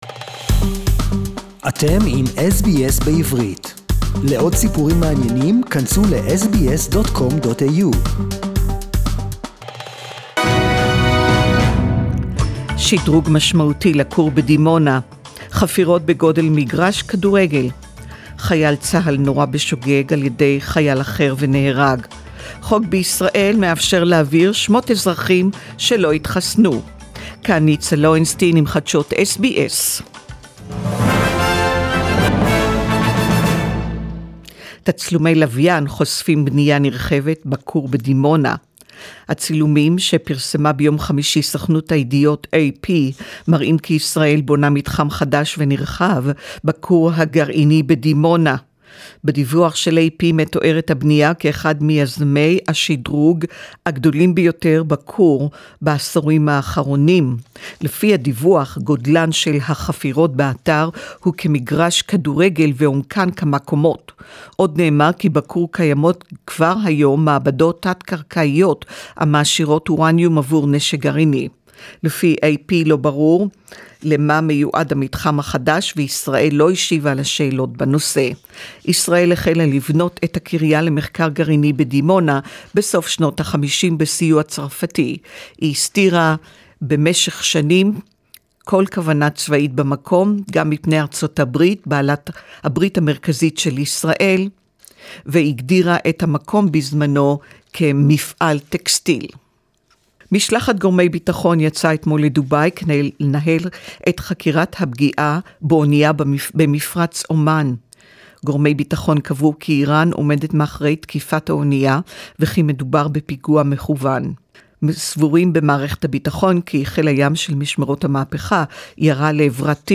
SBS News in Hebrew 28.2.2021